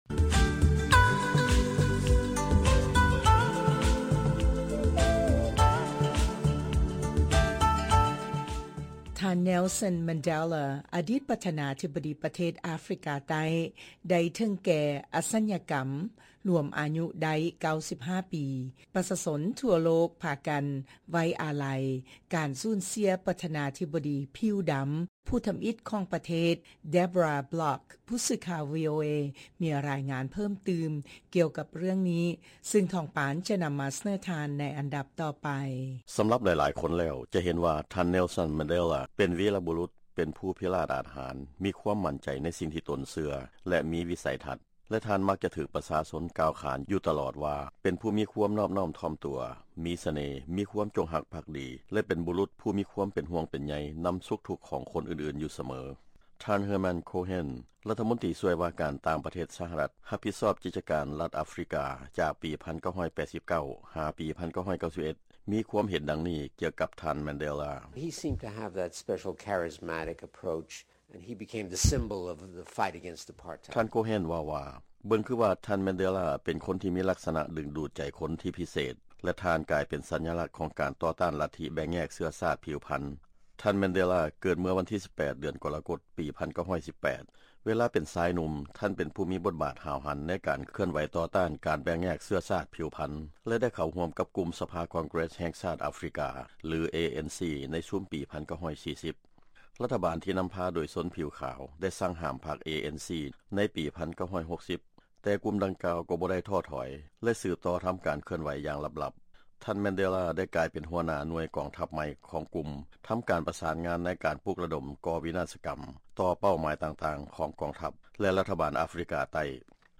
ຟັງລາຍງານ ຊີວະປະຫວັດຫຍໍ້ຂອງ ທ່ານ Nelson Mandela